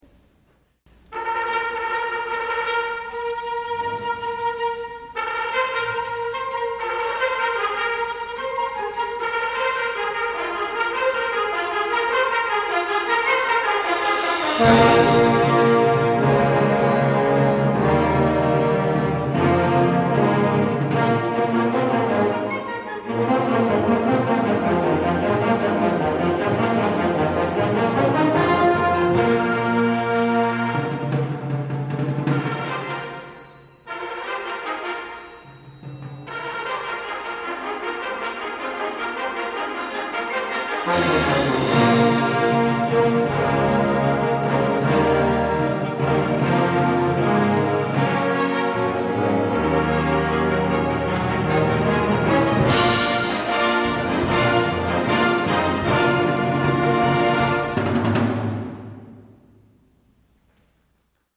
for Band (1989)